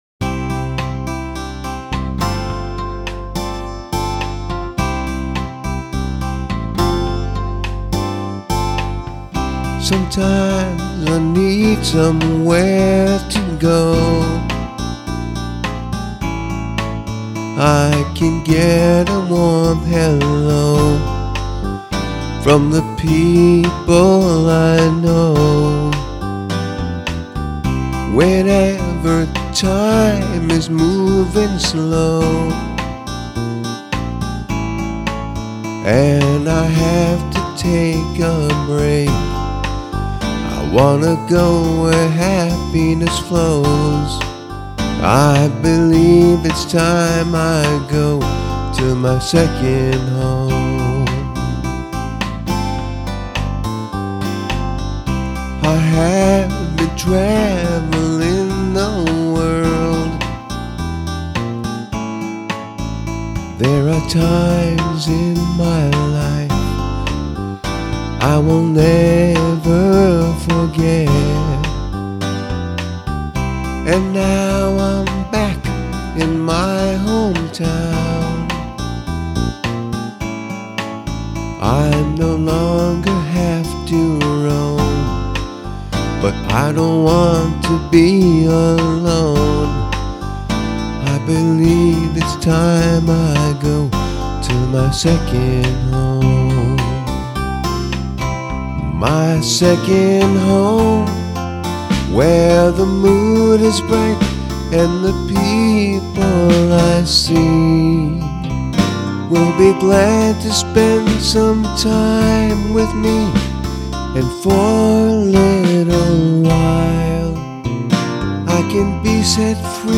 heartfelt song